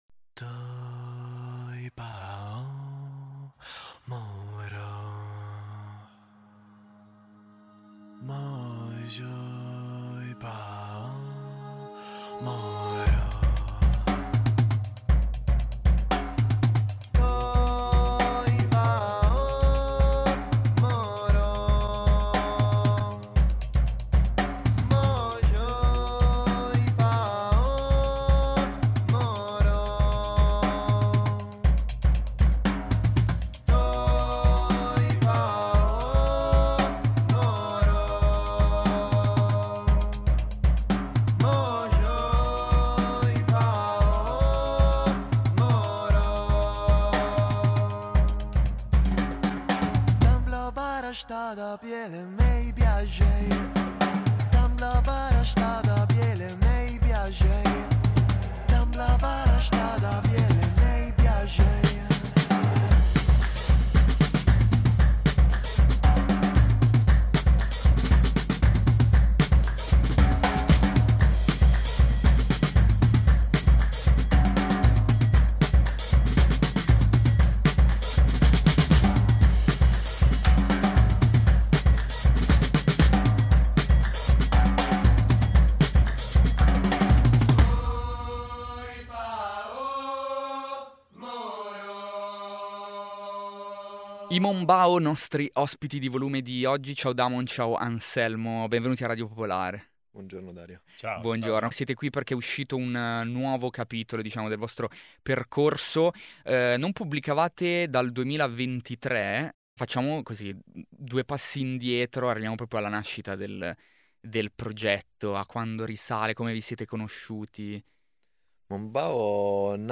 Nell’intervista, i Mombao ci hanno raccontato il pensiero dietro al disco disponibile unicamente in formato fisico, come fare per trollare adeguatamente le piattaforme streaming nel 2026 e il tour di listening party che arriva nelle case dei fan. Ascolta l’intervista ai Mombao.